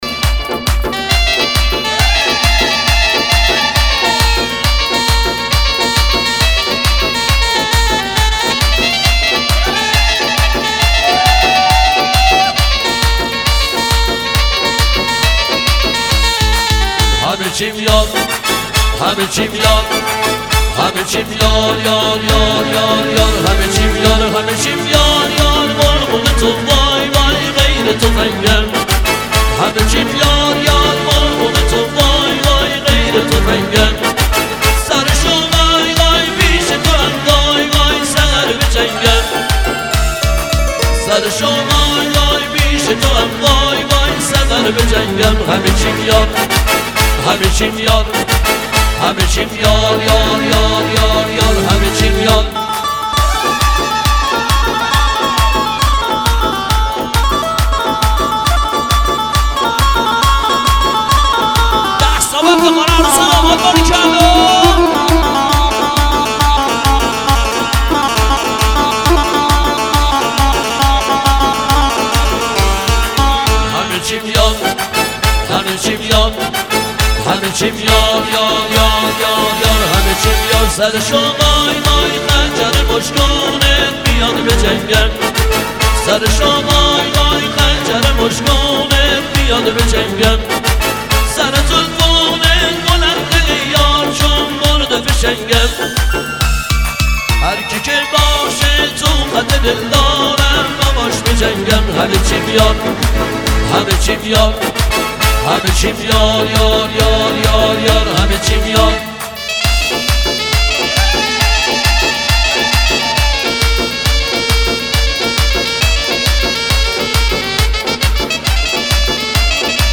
ترانه محلی